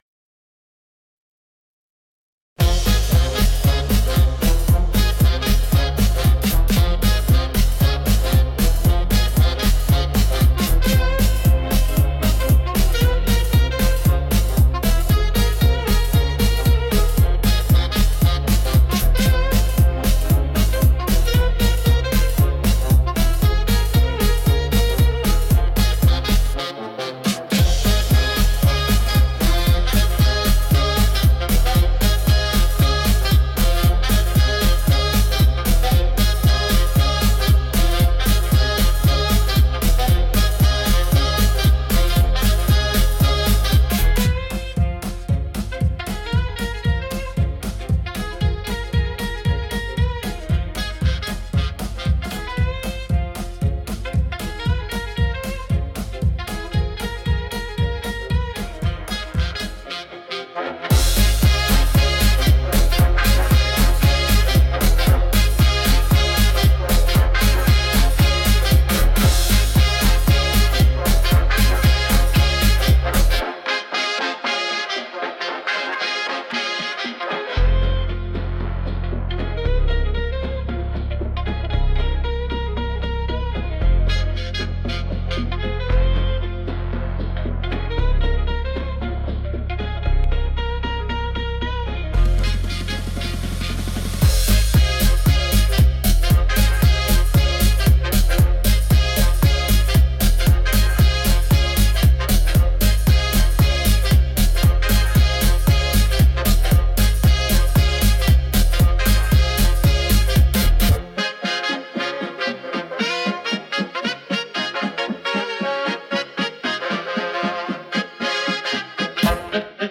Instrumental / 歌なし
今日は、踊りやすいリズムが心地よいダンスミュージックを作りました！